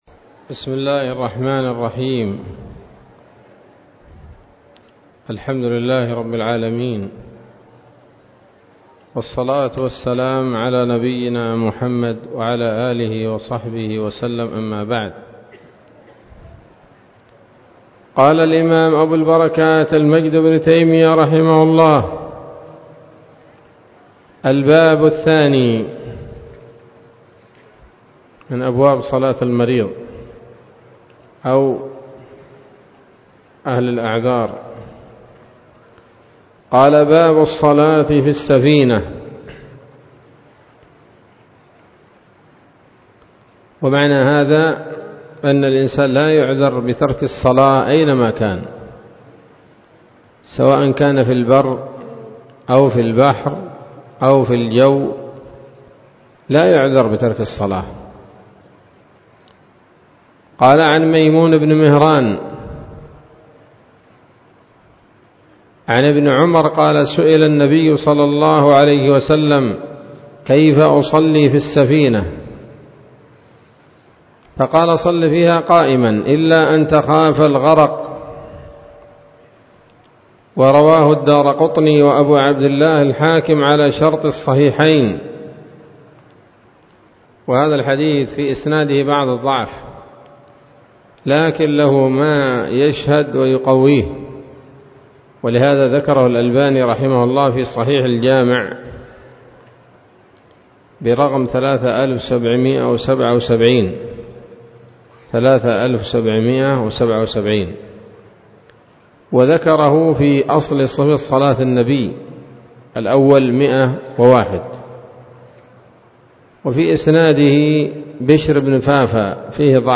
الدرس الثاني وهو الأخير من ‌‌كِتَاب صَلَاة الْمَرِيض من نيل الأوطار